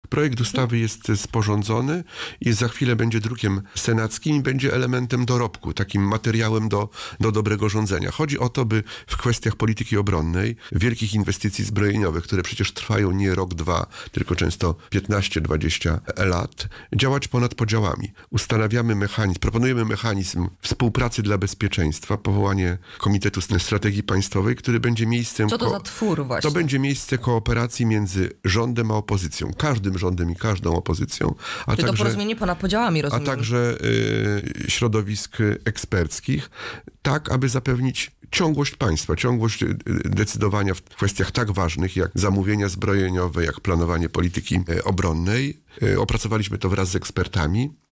Mam nadzieję, że Polacy na to oszustwo się nie nabiorą, -Marzę o tym, by za jakiś czas mur na granicy białoruskiej przestał istnieć, – W szkołach istnieją toksyczne relacje – mówił w audycji „Poranny Gość” senator K.M Ujazdowski.